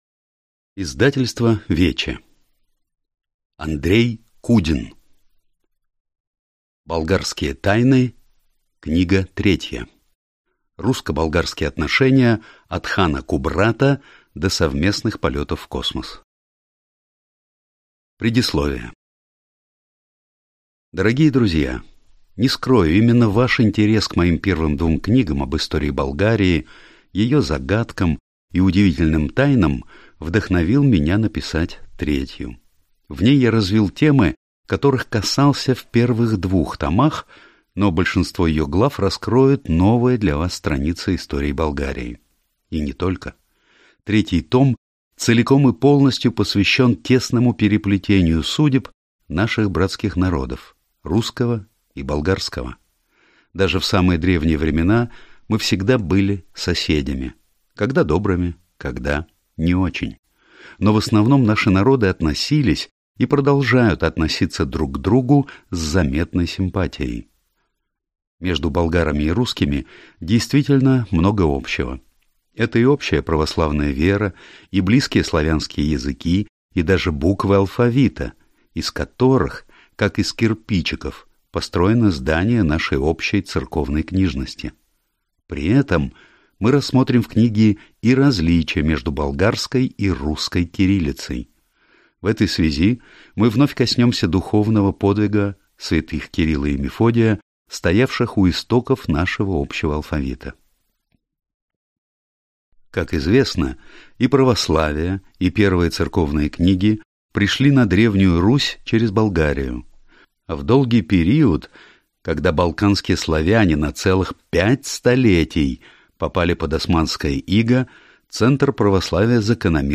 Аудиокнига Болгарские тайны. Русско-болгарские отношения от хана Кубрата до совместных полетов в космос | Библиотека аудиокниг